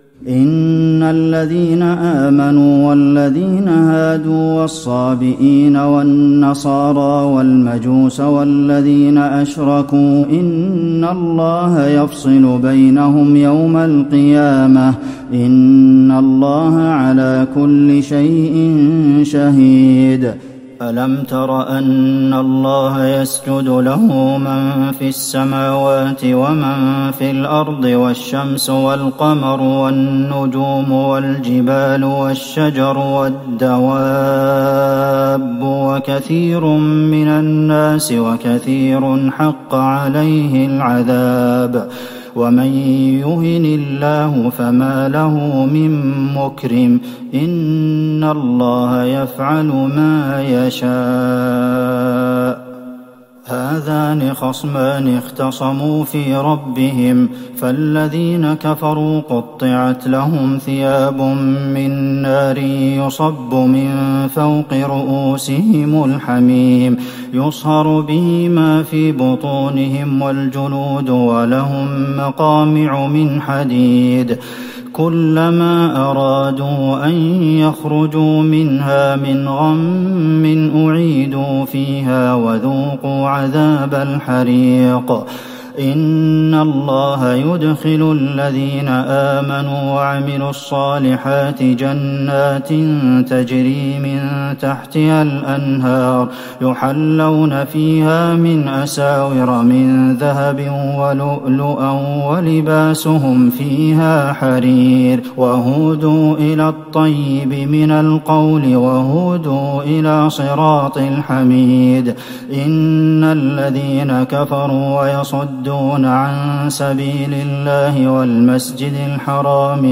تراويح ٢٢ رمضان ١٤٤١هـ من سورة الحج { ١٧-٧٨ } والمؤمنون { ١-٢٢ } > تراويح الحرم النبوي عام 1441 🕌 > التراويح - تلاوات الحرمين